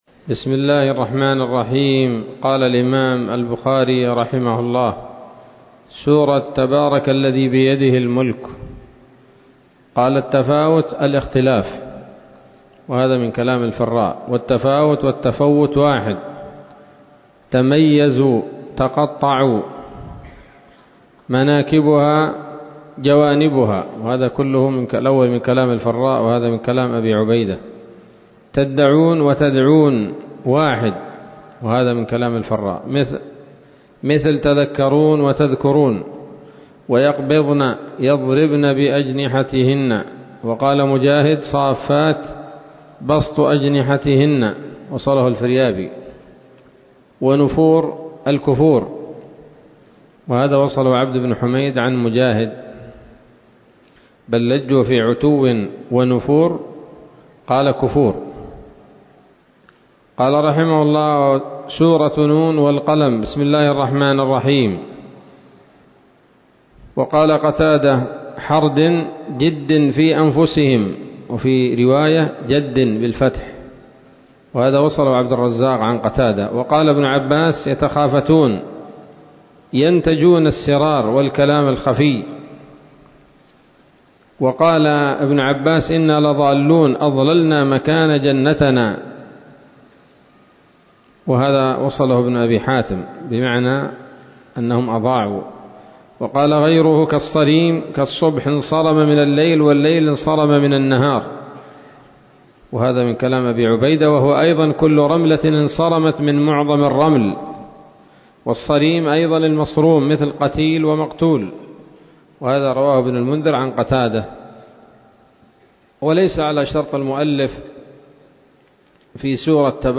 الدرس الواحد والسبعون بعد المائتين من كتاب التفسير من صحيح الإمام البخاري